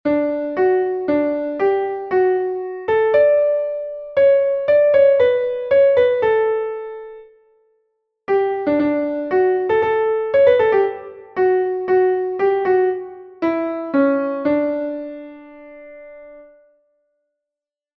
Entoación a capella
Melodía 2/3 en Re M